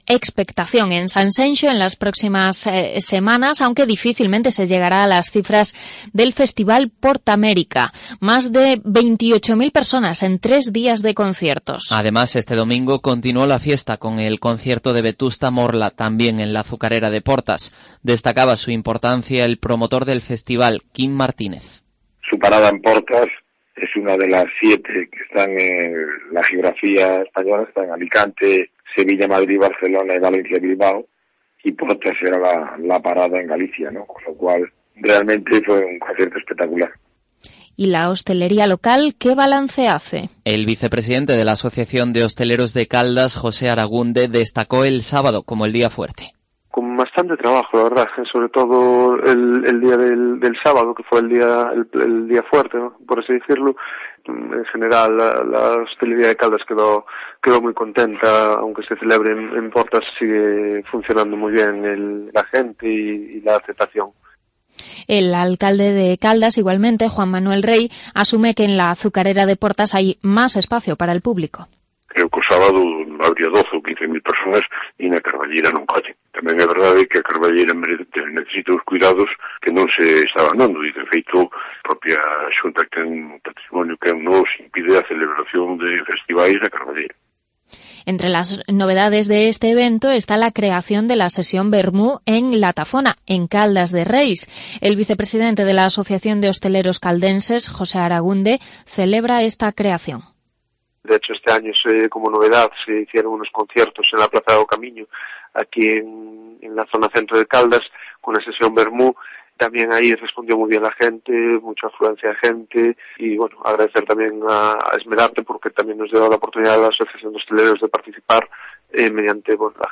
El alcalde caldense, Juan Manuel Rey, propone apariciones de los cantantes estrella o clases magistrales de los chefs del festival que se desarrolla en Portas
Por su parte, en entrevista en COPE, el alcalde de la villa caldense, Juan Manuel Rey, adelantó varias propuestas para las sesiones vermú en el municipio que dirige: "Igual hai que diversificar máis, buscar que nalgún momento algunha das primeiras figuras fagan unha aparición, aínda que só sexa para saudar e unha canción. E o mesmo pode pasar cos cociñeiros, cunha conferencia ou un minicurso".